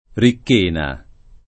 [ rikk % na ]